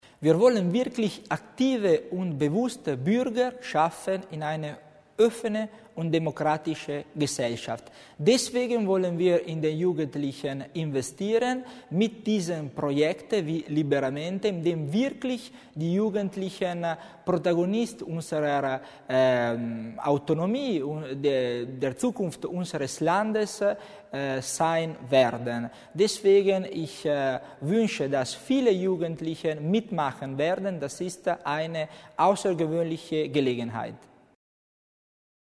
Landesrat Tommasini über das Projekt "LiberaMente"